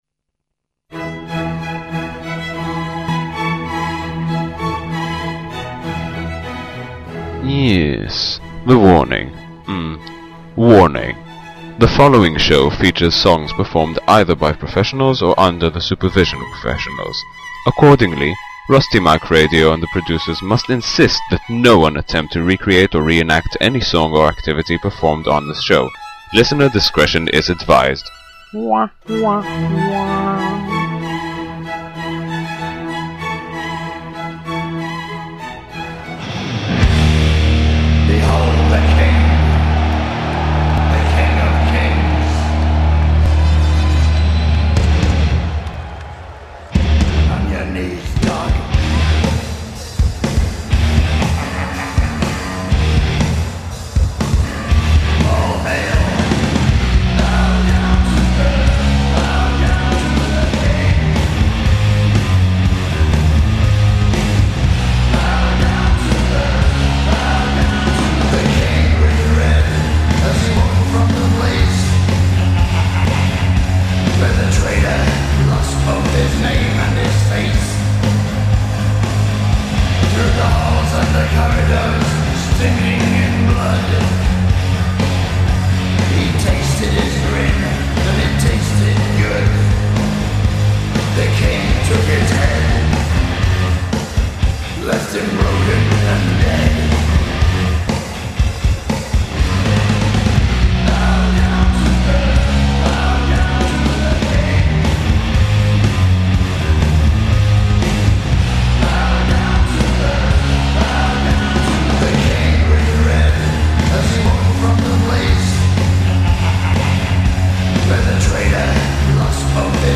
entrance music
theme music